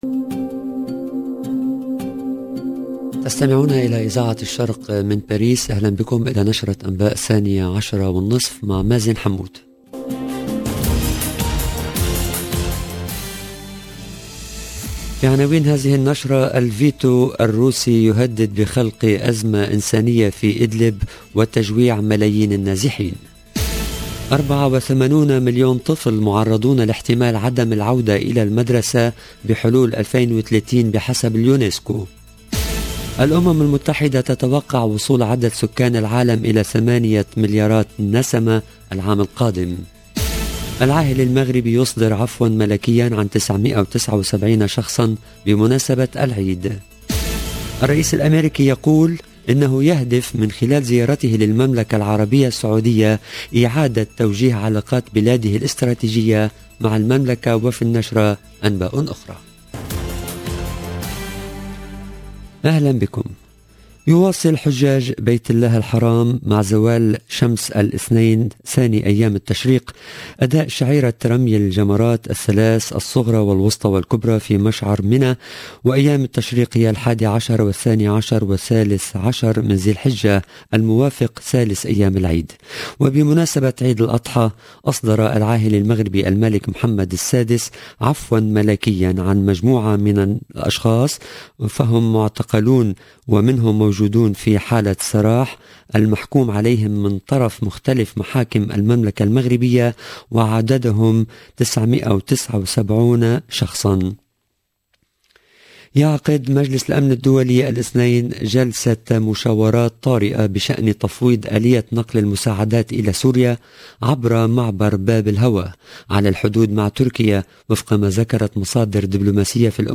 LE JOURNAL EN LANGUE ARABE DE MIDI 30 DU 11/07/22